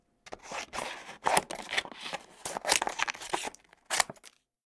Tijera cortando un cartón
tijera
Sonidos: Acciones humanas
Sonidos: Oficina